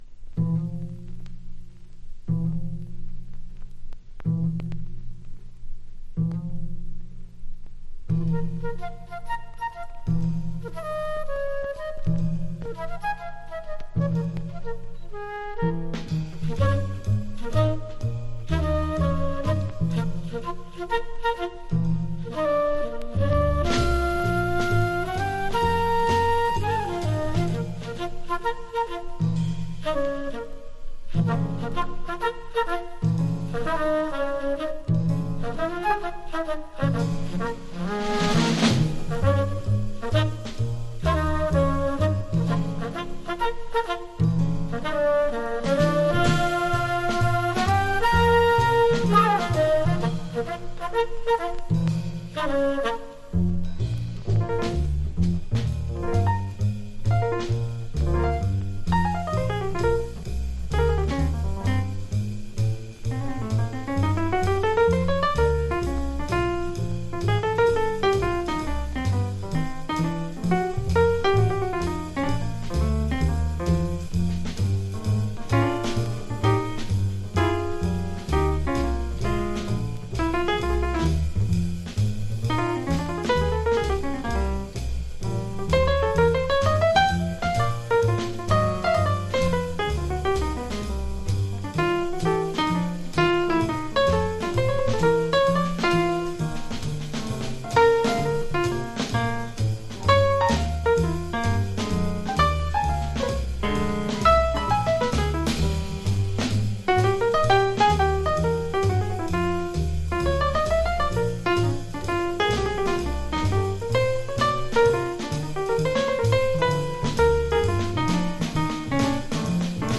4曲トリオ。